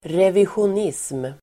Ladda ner uttalet
Uttal: [revisjon'is:m]